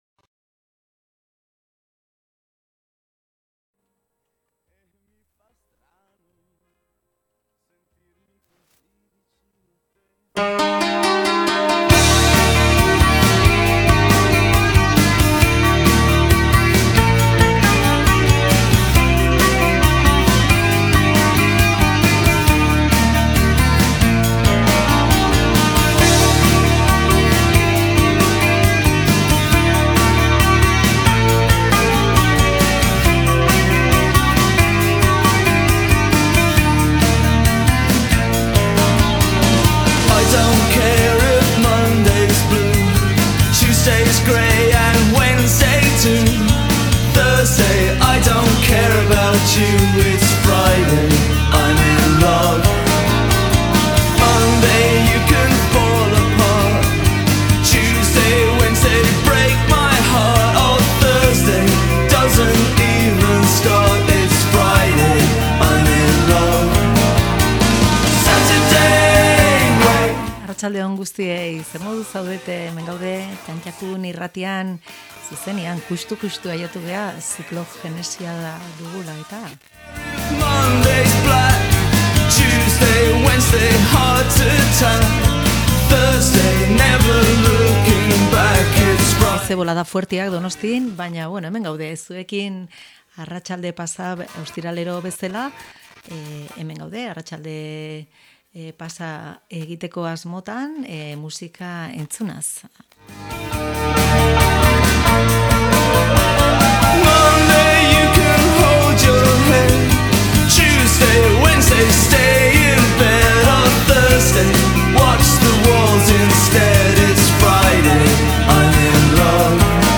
ipuin musikala